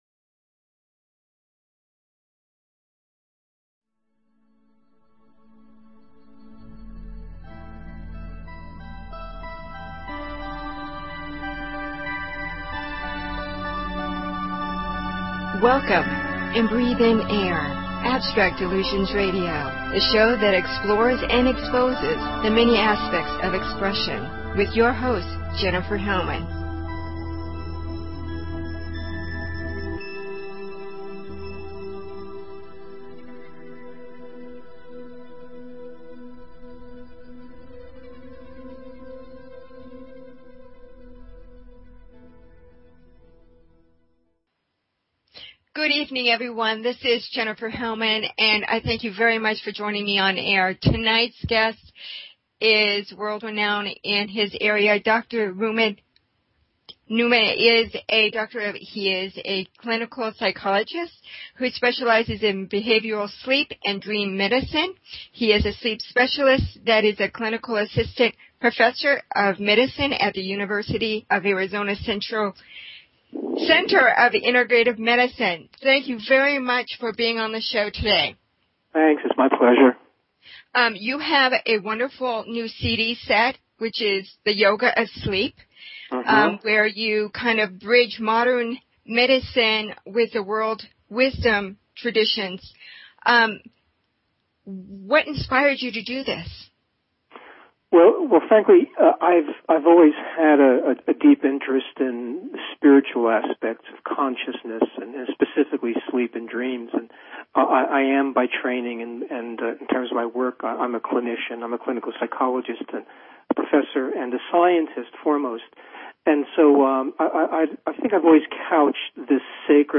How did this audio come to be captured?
It was an interesting conversation with so many having trouble sleeping these days.